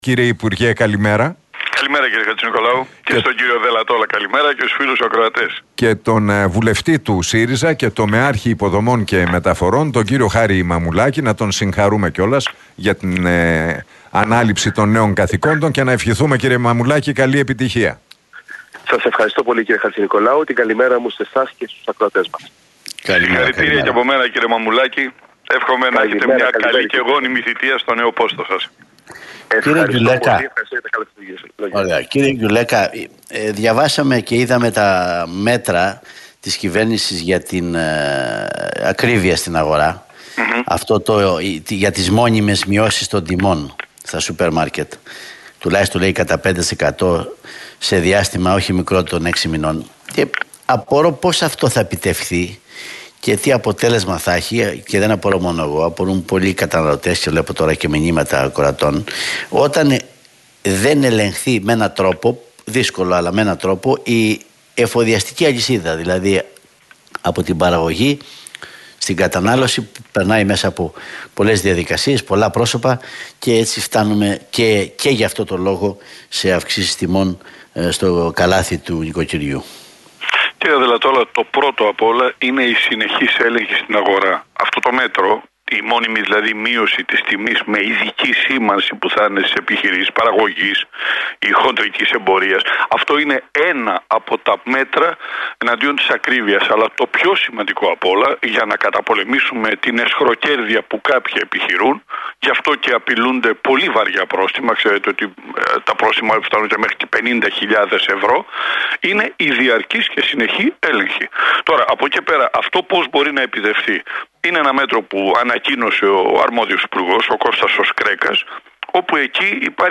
Debate Γκιουλέκα και Μαμουλάκη στον Realfm 97,8